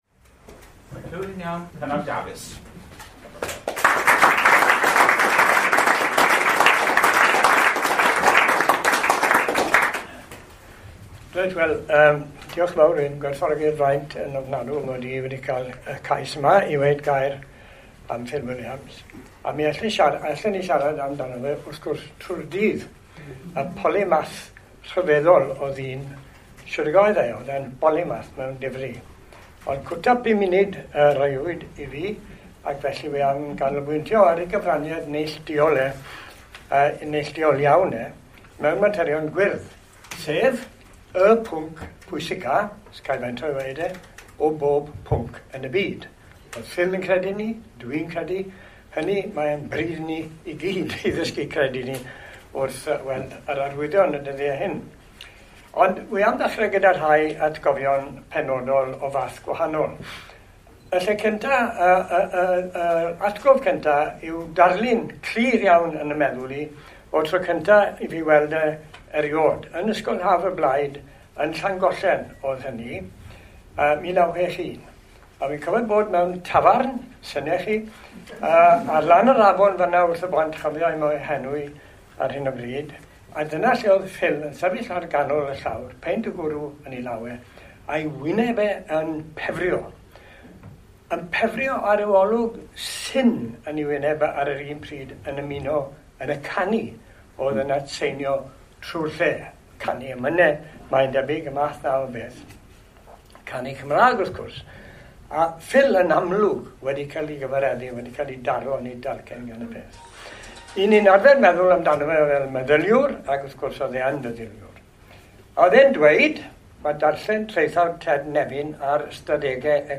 yng Nghynhadledd Wanwyn Plaid Cymru yn y Pafiliwn, Llangollen